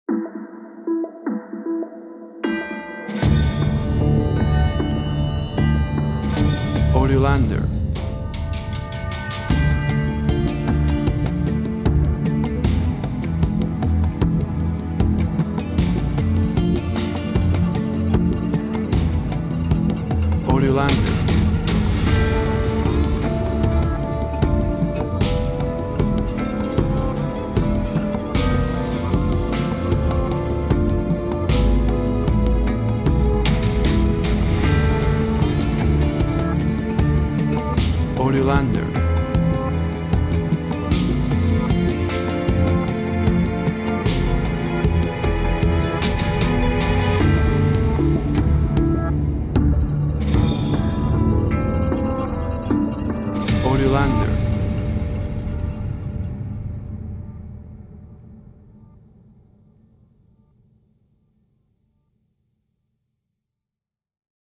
WAV Sample Rate 24-Bit Stereo, 44.1 kHz
Tempo (BPM) 76